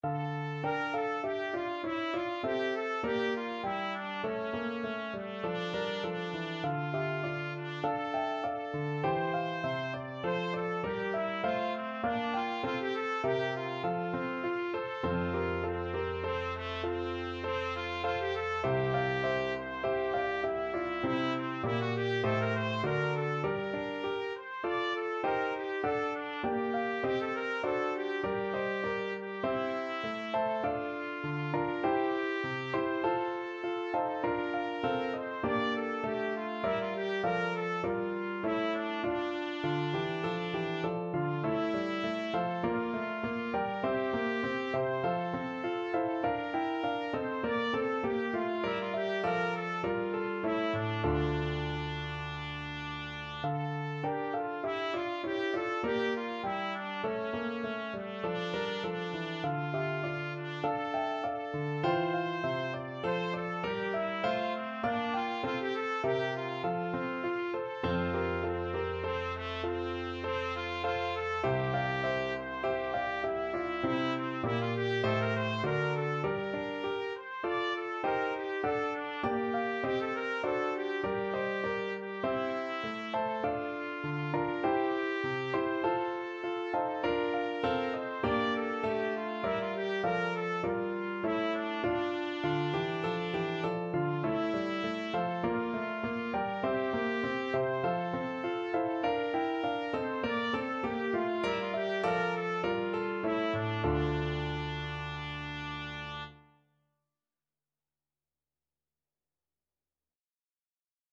D minor (Sounding Pitch) E minor (Trumpet in Bb) (View more D minor Music for Trumpet )
4/4 (View more 4/4 Music)
Trumpet  (View more Intermediate Trumpet Music)
Classical (View more Classical Trumpet Music)